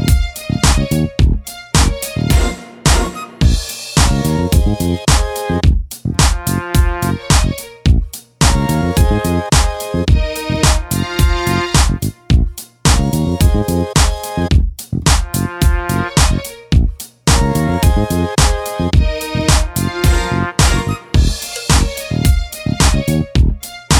no Backing Vocals R'n'B / Hip Hop 3:18 Buy £1.50